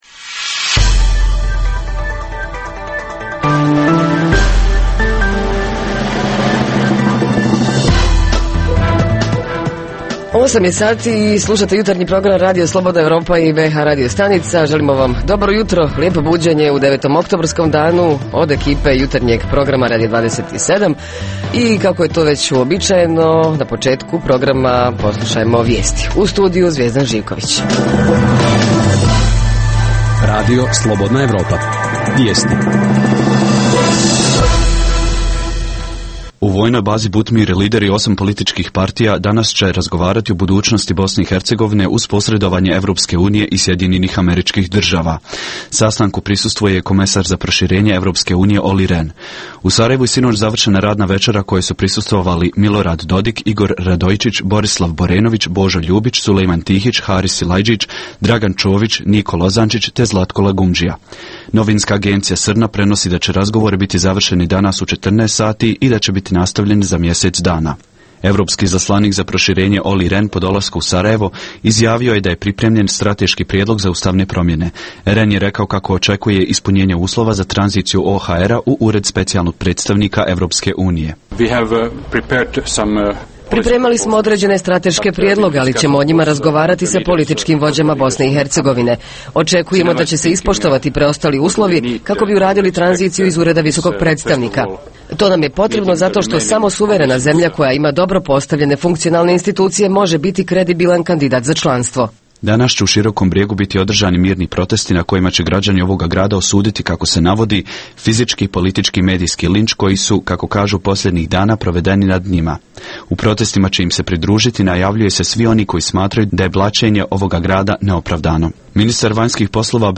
Da li će škole (osnovne i srednje) spremno dočekati hladnije jesenje dane i zimu? Reporteri iz cijele BiH javljaju o najaktuelnijim događajima u njihovim sredinama.
Redovni sadržaji jutarnjeg programa za BiH su i vijesti i muzika.